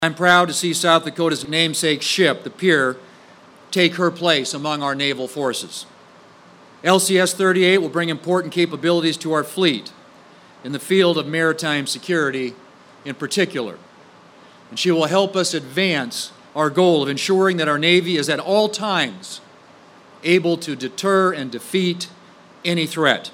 PANAMA CITY, F.L.(KCCR)- The U-S-S Pierre officially joined the fleet of the United States Navy Saturday morning with a traditional Commissioning Ceremony at Port Panama City Florida.
Senator John Thune says the U-S-S Pierre will enhance and improve the defense of America.